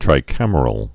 (trī-kămər-əl)